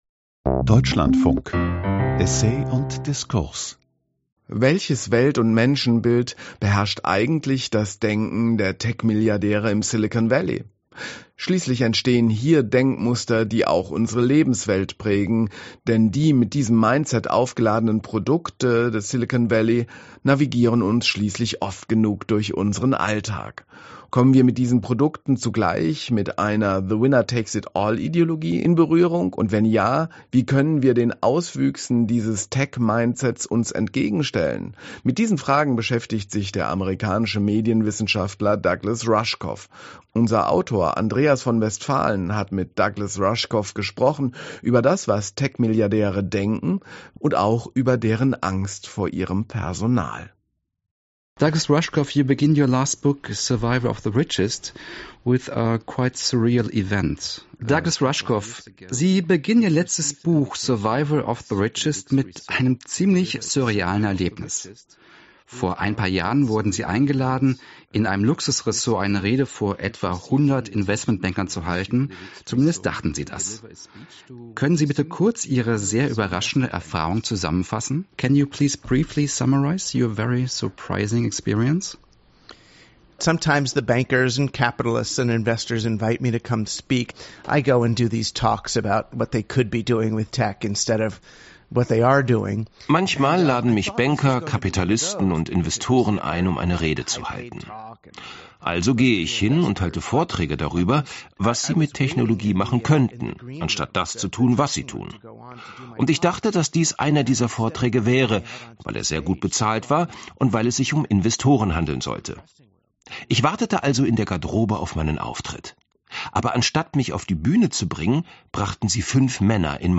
Douglas Rushkoff Survival of the Richest Das �berleben der Reichsten 2022, 250 Seiten Warum wir vor den Tech-Milliard�ren noch nicht einmal auf dem Mars sicher sind Eine scharfsinnige Analyse Audio 2024 Interview dlf 29min